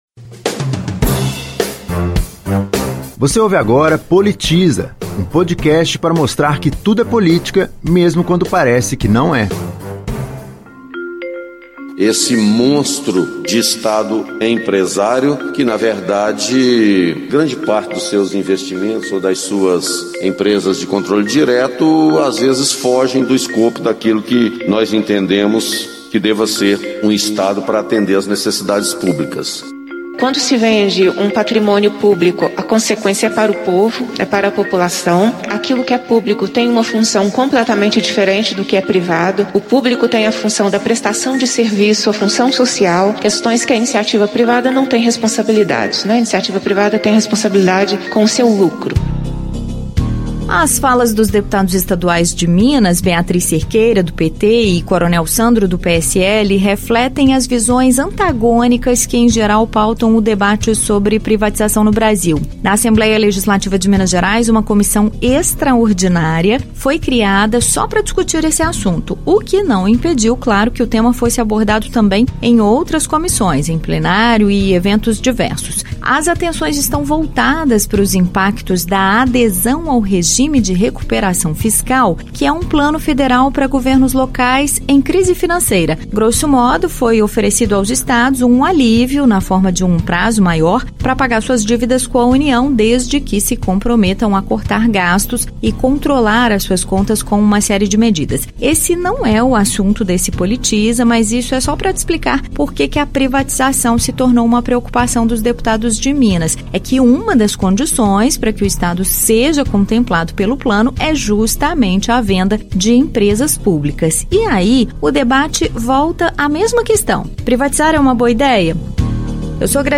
Ele esteve na Comissão Extraordinária das Privatizações, da Assembleia Legislativa de Minas Gerais, e é o convidado deste episódio do Politiza, para explicar porque defende que uma privatização bem sucedida, tanto do ponto de vista econômico quanto social, depende da qualidade do governo.